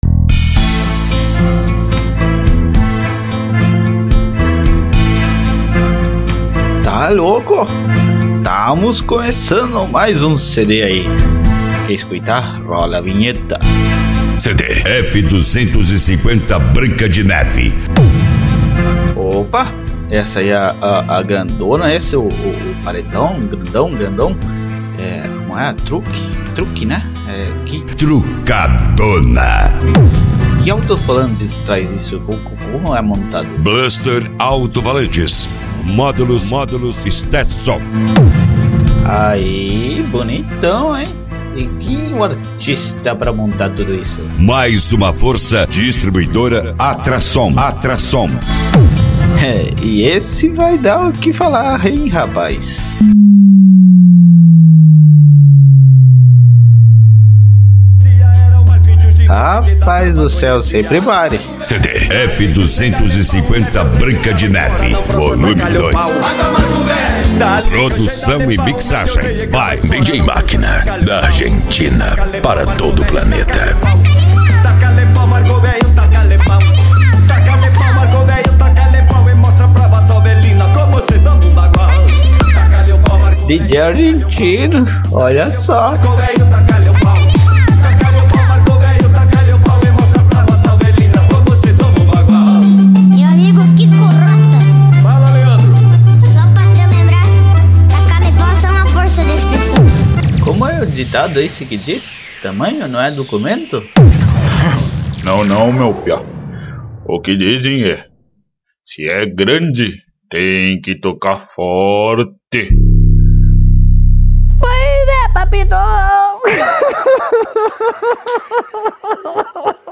Funk Nejo
Hard Style
PANCADÃO